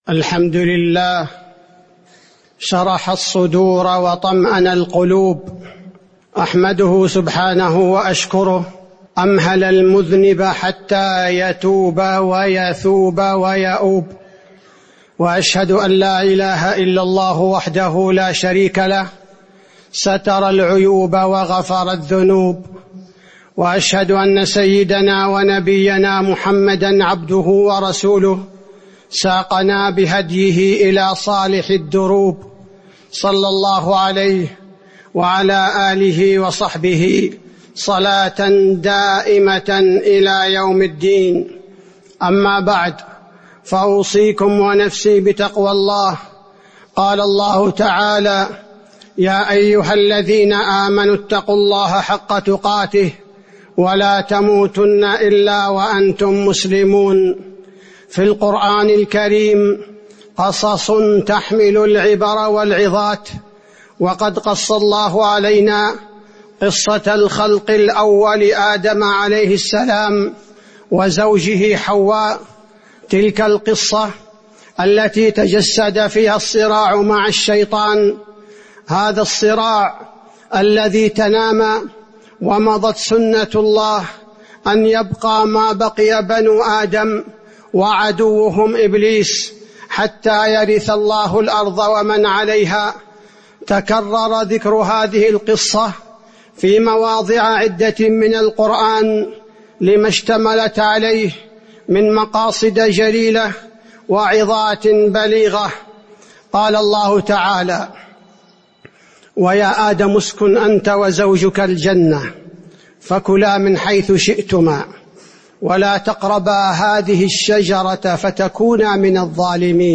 تاريخ النشر ٨ جمادى الأولى ١٤٤٤ هـ المكان: المسجد النبوي الشيخ: فضيلة الشيخ عبدالباري الثبيتي فضيلة الشيخ عبدالباري الثبيتي من مقاصد قصة آدم وإبليس